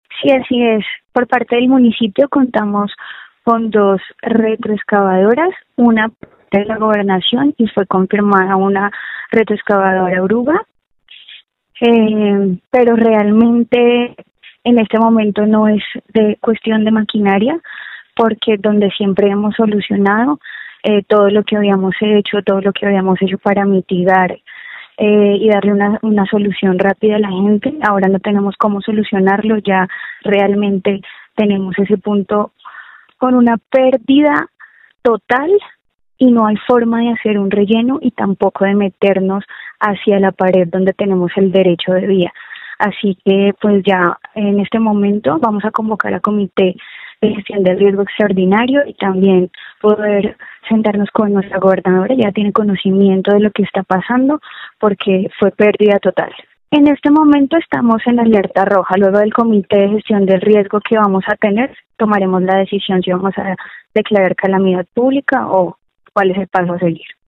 Escuche a Gina Vanesa Silva, alcaldesa de Herveo, quien explica esta difícil situación que afronta su municipio:
Alcaldesa-de-Herveo-.mp3